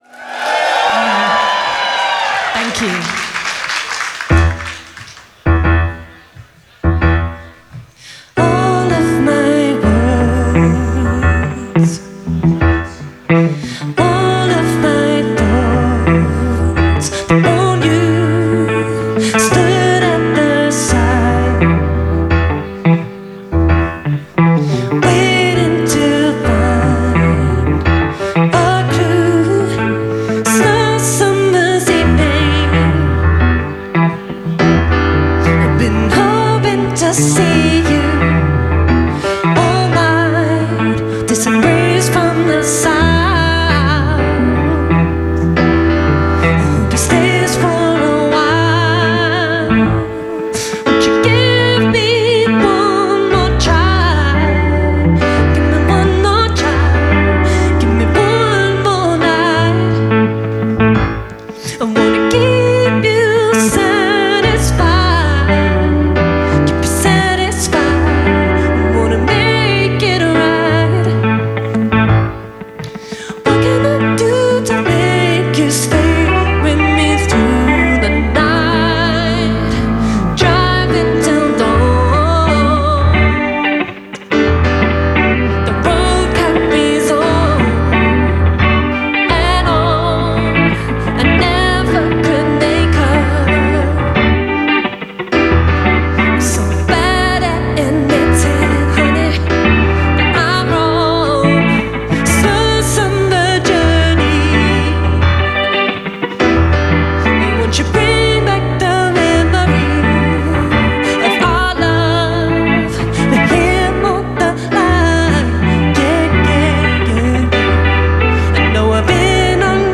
Heading into mellow territory this lunchtime
R&B and Soul artists of the 70s/early 80s